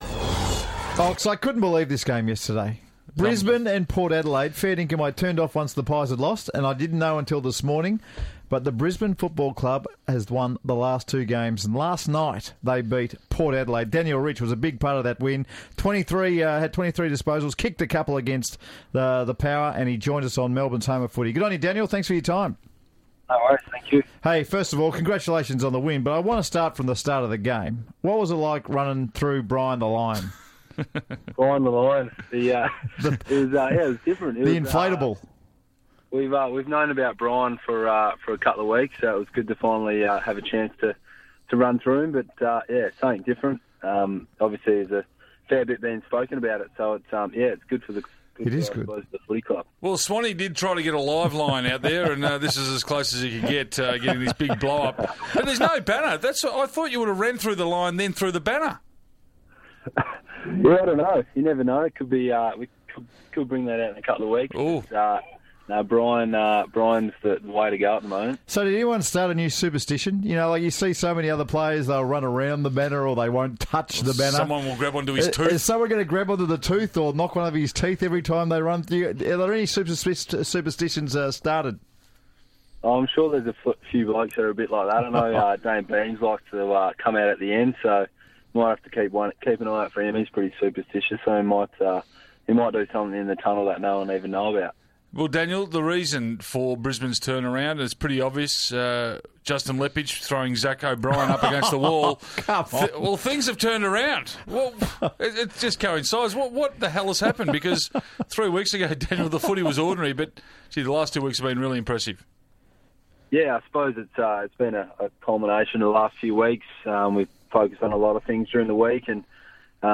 Brisbane midfielder Daniel Rich speaks to The Run Home about the Lions turnaround in form.